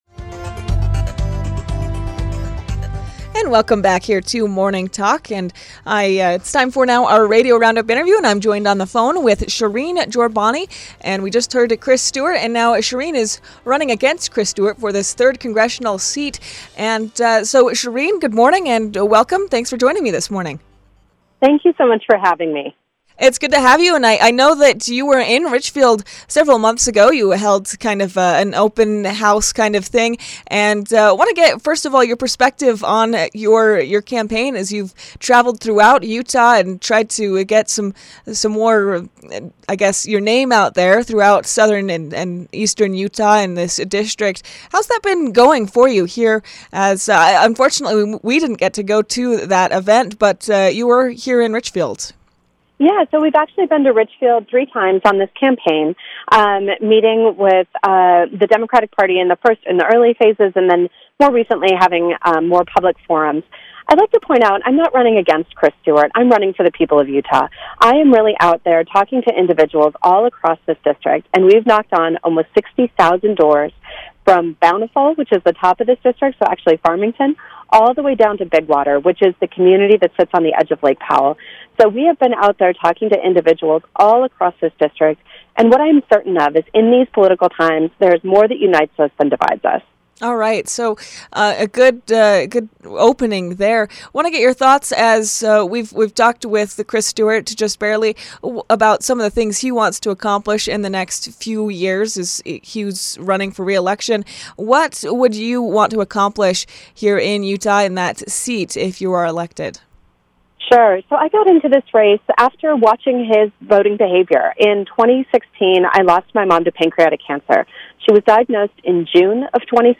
joined Morning Talk on KSVC Monday morning.